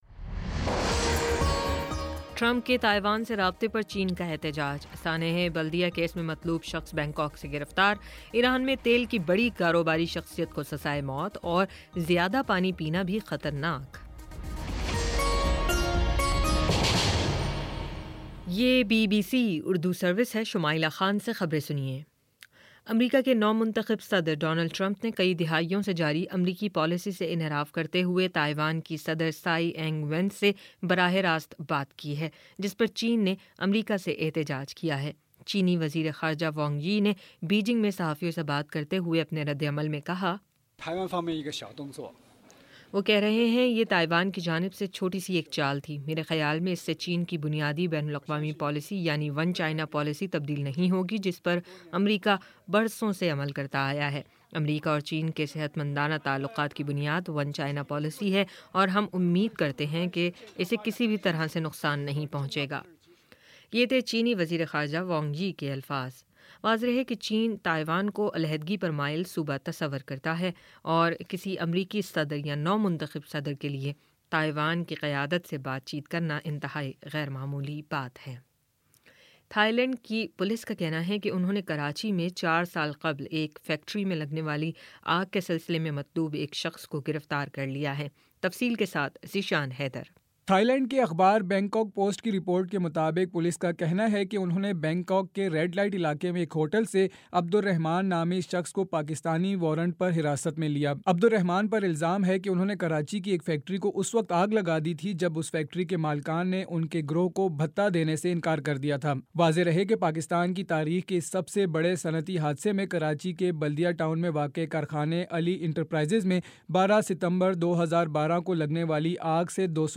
دسمبر 03 : شام سات بجے کا نیوز بُلیٹن